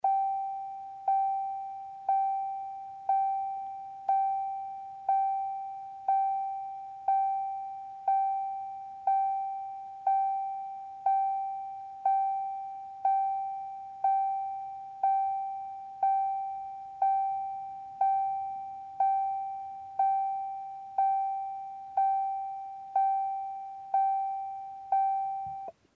Звуки дверей автомобиля
Звук оповещающий открытую дверь автомобиля (писк)